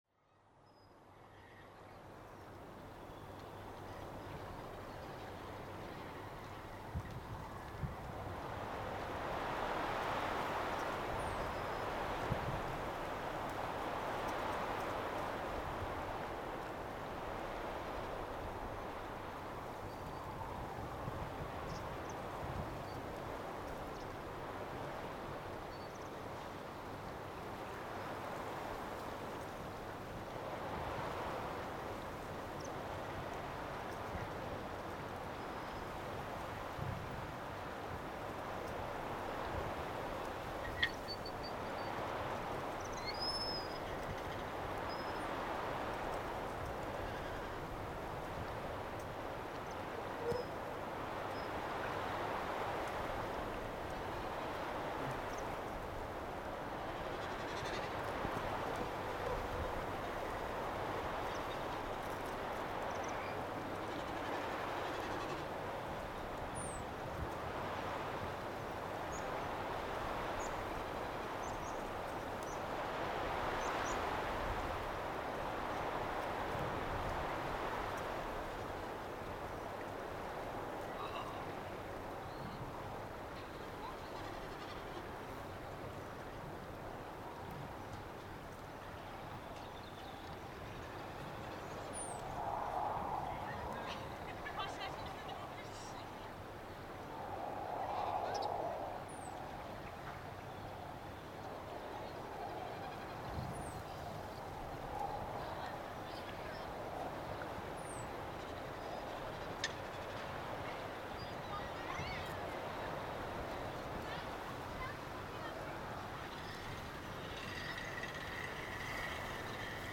Opus for power line, bass, wind and birds.
Tónverk fyrir háspennustrengi, bassa, vind og fugla.
That happened in beginning of June 2012 when I was at Krossholt at Barðastönd , in the northwest of Iceland.
The rumbling bass beat was noticeable all night along. During the night the wind started to blow from east with strong gusts. Suddenly nearby power line started to give a strange sound and the niggling beat from the neighborhood started to be interesting. In combination with the wind, power line, birdsong from the field and nearby cliff it started to be like a music from other planet.
The intro is more than two minutes long, so just lay back in your chair, relax and listen.
Söngur mó- og bjargfugla bættist svo við í bakgrunni eins og til að fullkomna tónverkið.